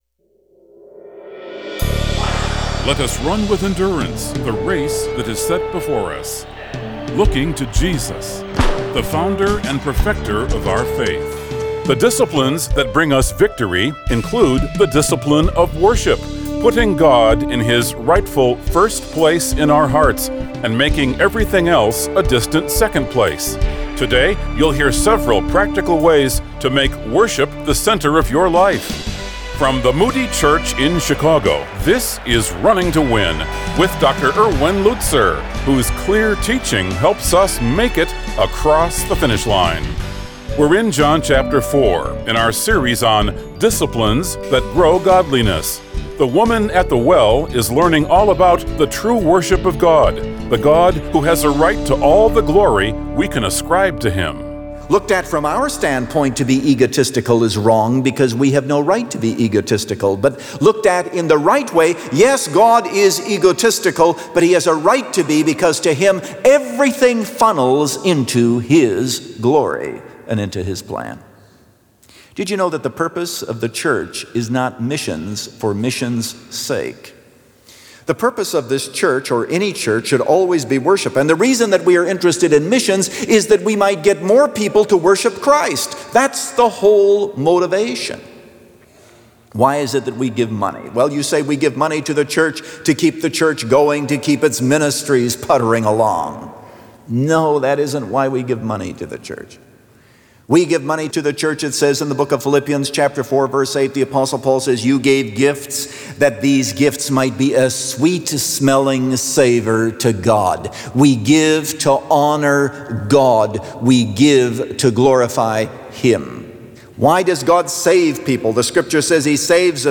The Discipline Of Worship – Part 3 of 3 | Radio Programs | Running to Win - 15 Minutes | Moody Church Media